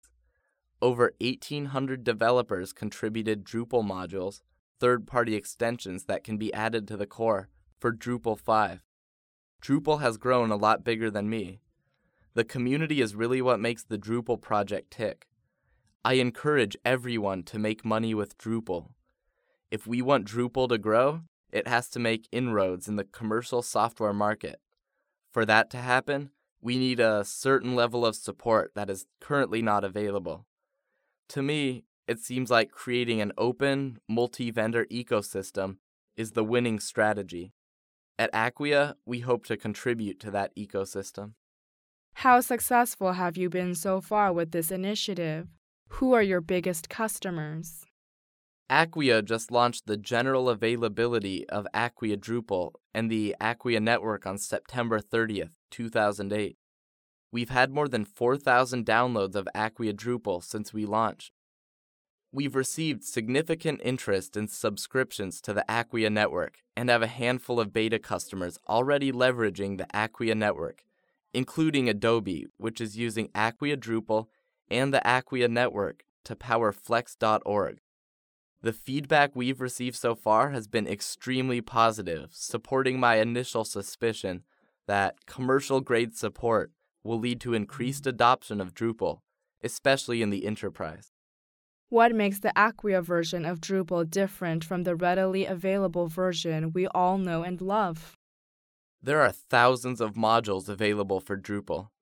创业成功人士访谈录 第34期:开源模式将会继续成长(4) 听力文件下载—在线英语听力室